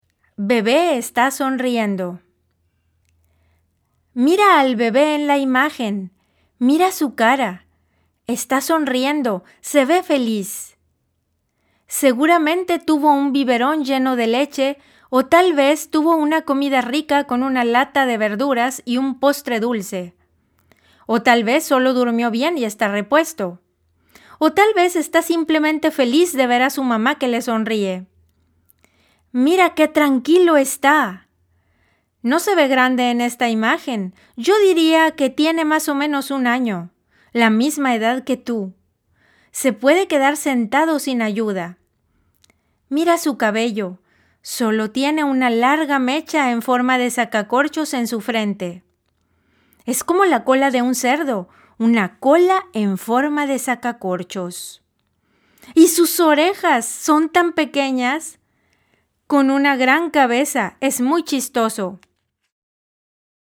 Sensibilisation des oreilles de bébé grâce à des séquences de bavardage aux longues phrases pour que bébé capte la musicalité et le rythme de la langue espagnole
À chaque fois que cela a été possible Génération Bilingue a introduit les sons correspondants : le chien qui aboie, le chat qui miaule… pour que bébé commence à mettre du sens sur le texte qu’il entend.